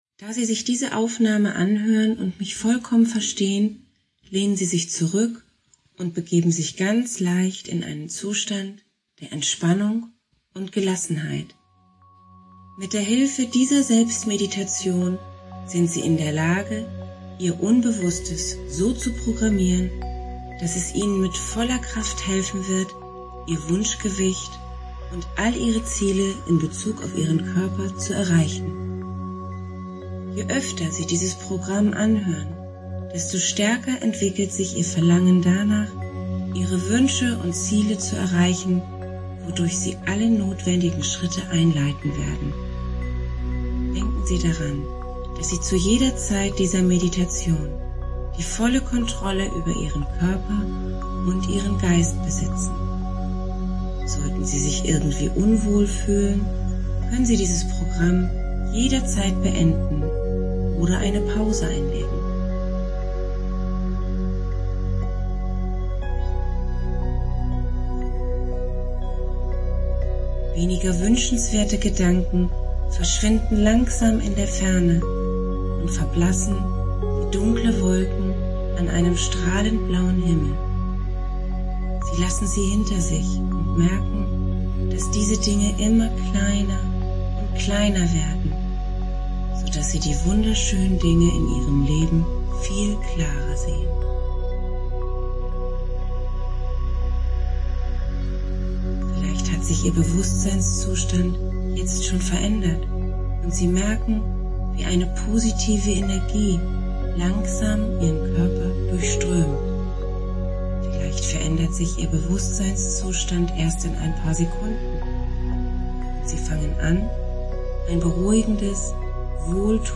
Unbewusst Schlank - Audio Meditationen
Meditation-1.-3.1.mp3